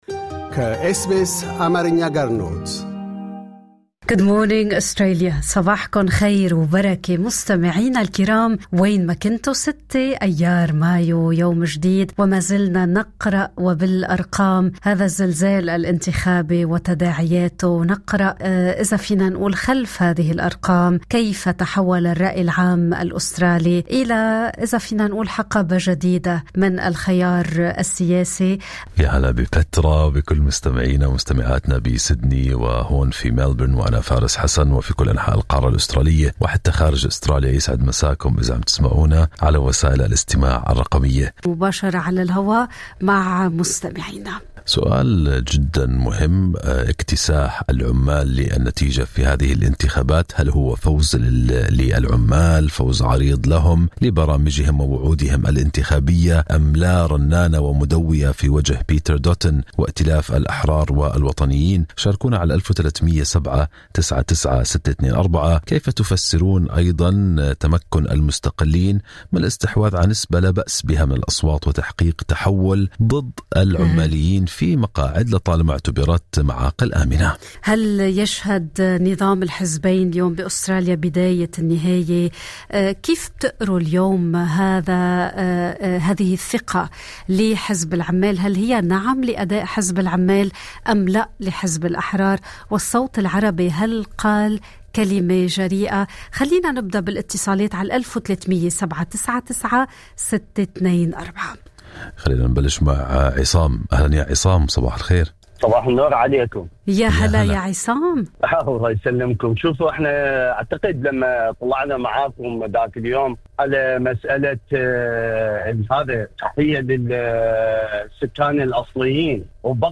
سؤالًا في البث المباشر عن رأي المستمعين بهذه النتيجة وأسبابها.